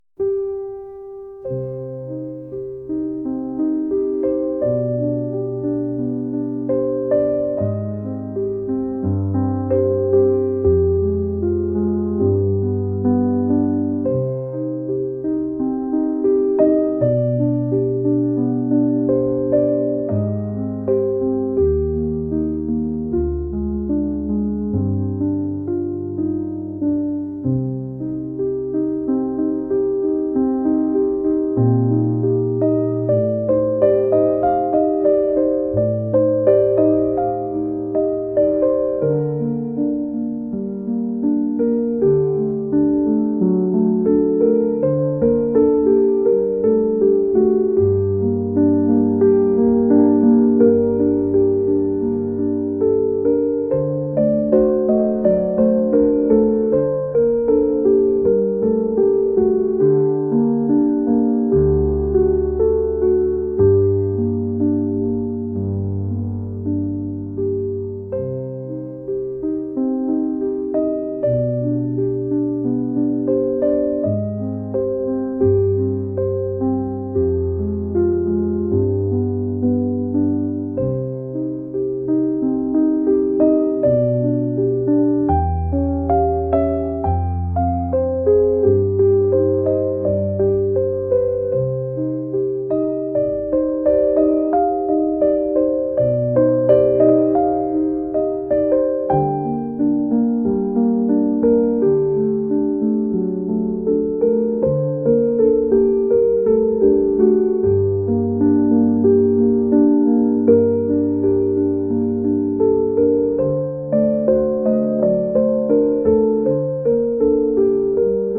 Мелодия элегической печали